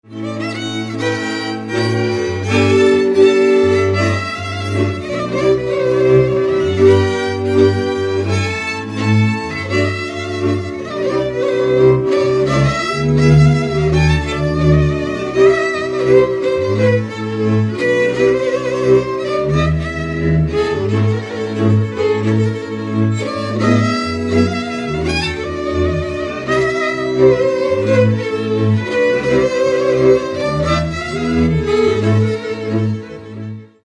Dallampélda: Hangszeres felvétel
Erdély - Szolnok-Doboka vm. - Ördöngösfüzes
hegedű
kontra
bőgő
Műfaj: Katonakísérő
Stílus: 3. Pszalmodizáló stílusú dallamok
Kadencia: 4 (b3) VII 1